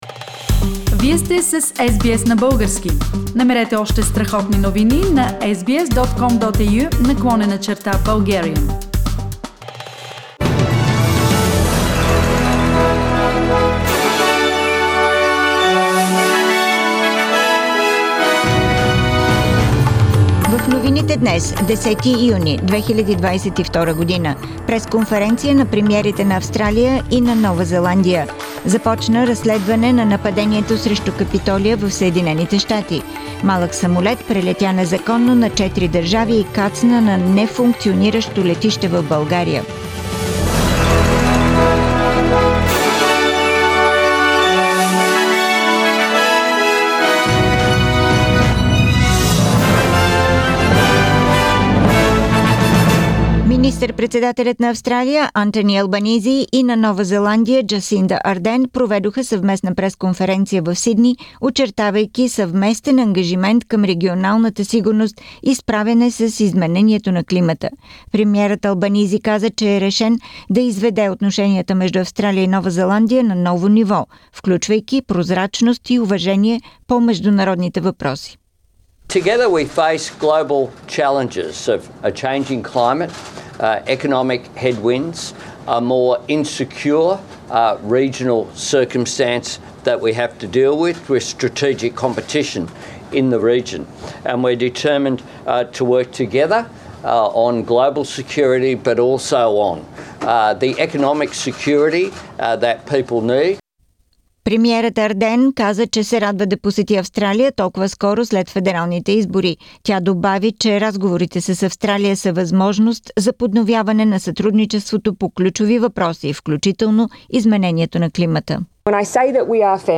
Weekly Bulgarian News – 10th Jun 2022